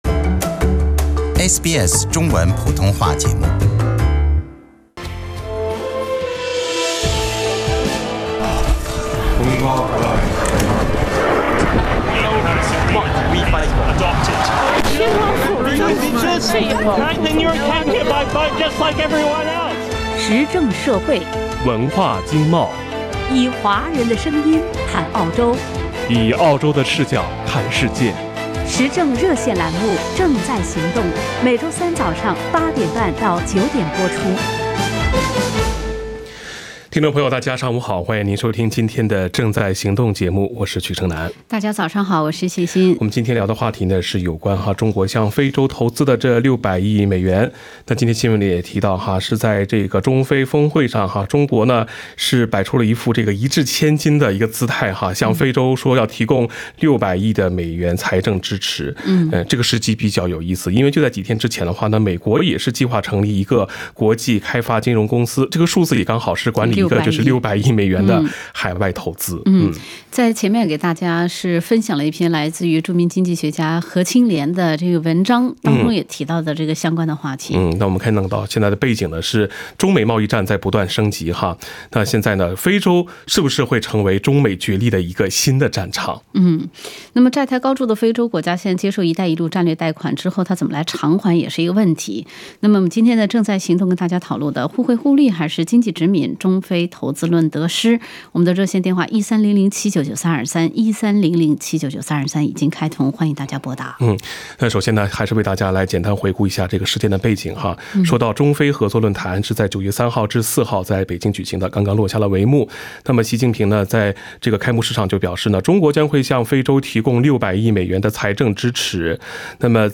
以上为听众发言节选，不代表本台立场） 时政热线节目《正在行动》逢周三上午8点30分至9点播出。